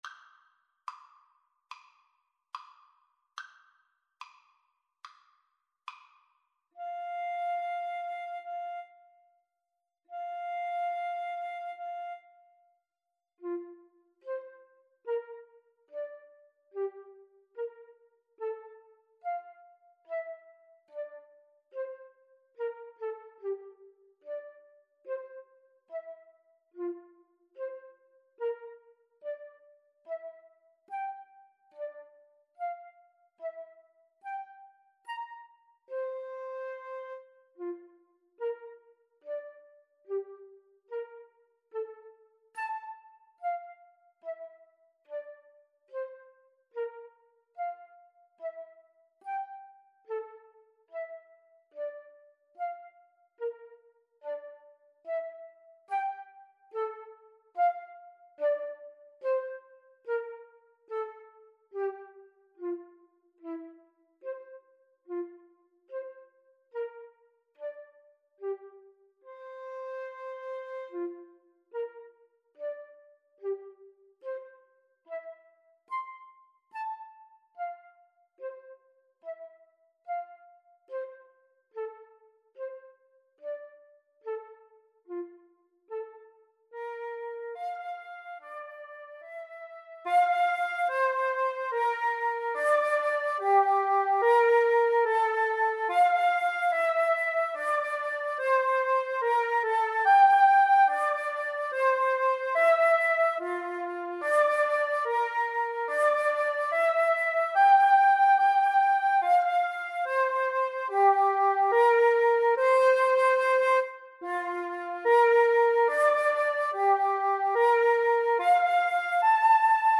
4/4 (View more 4/4 Music)
Andante. Nobilmente e semplice = 72
Flute Duet  (View more Easy Flute Duet Music)
Classical (View more Classical Flute Duet Music)